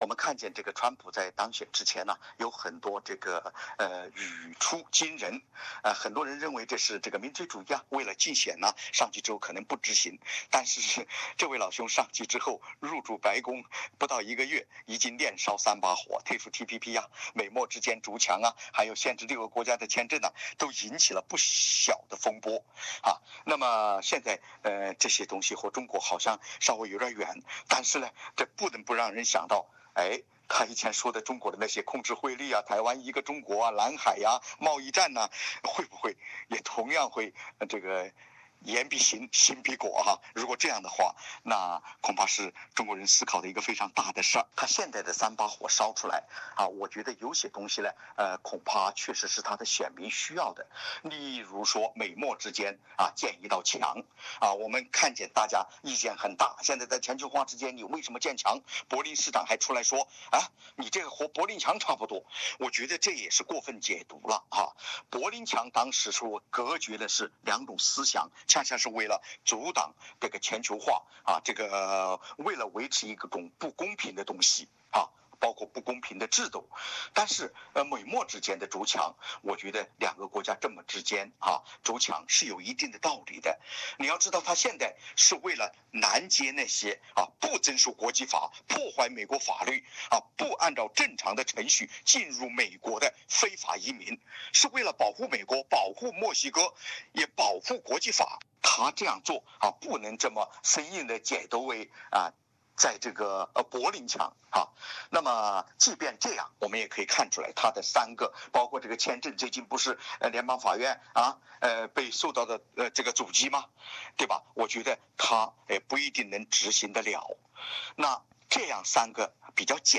时事评论员杨恒均点评认为，对于中国来说，机遇和挑战并存。